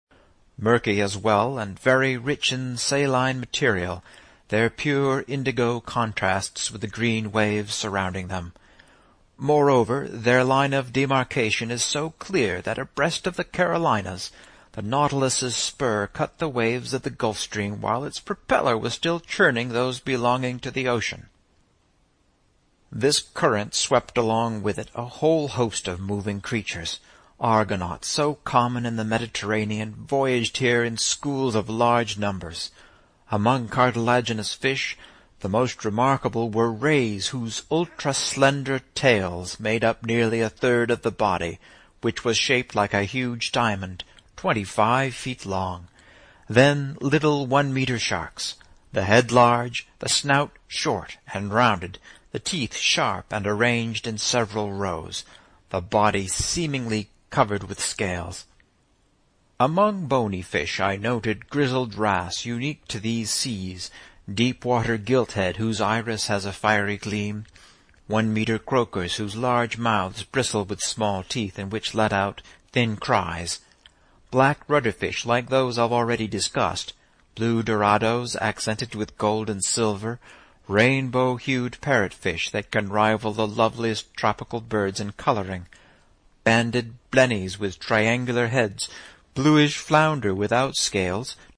在线英语听力室英语听书《海底两万里》第514期 第32章 海湾暖流(5)的听力文件下载,《海底两万里》中英双语有声读物附MP3下载